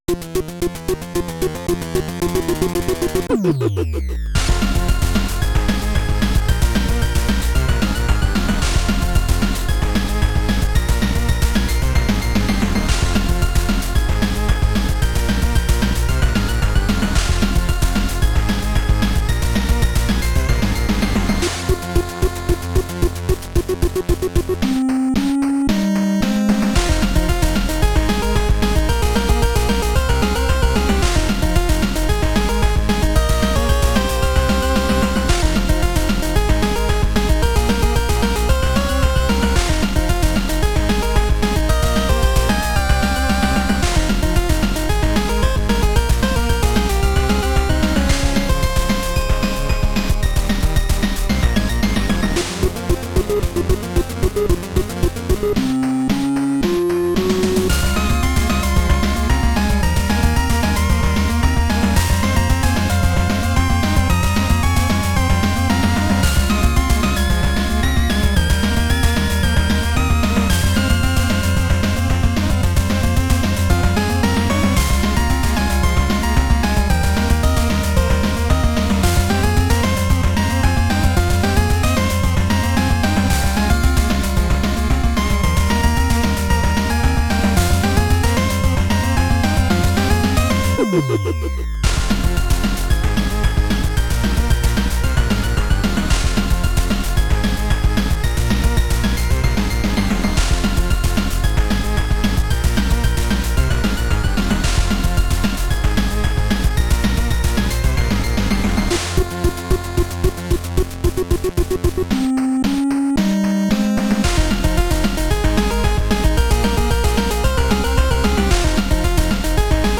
This is Journey, a collection of chiptune songs I've made over the past 9 years.
Modules used are 2a03+VRC6, mostly.Inspired by a plethora of genres.